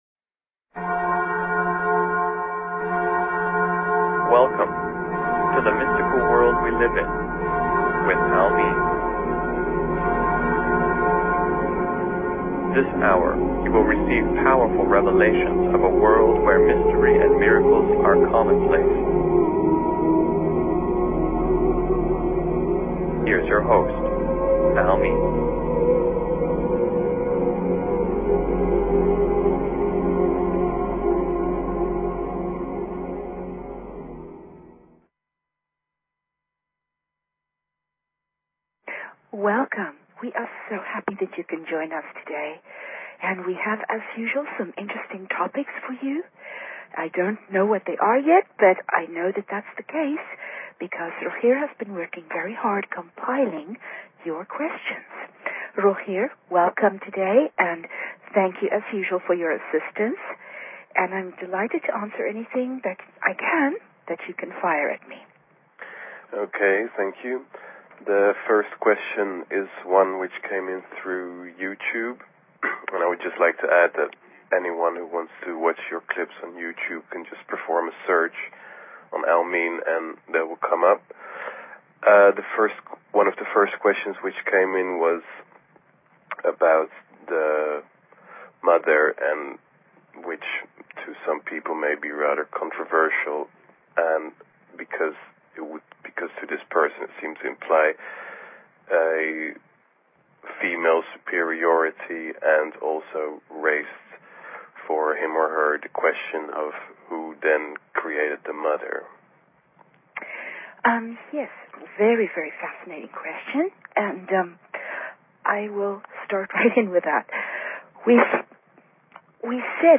Talk Show Episode, Audio Podcast, The_Mystical_World_we_live_in and Courtesy of BBS Radio on , show guests , about , categorized as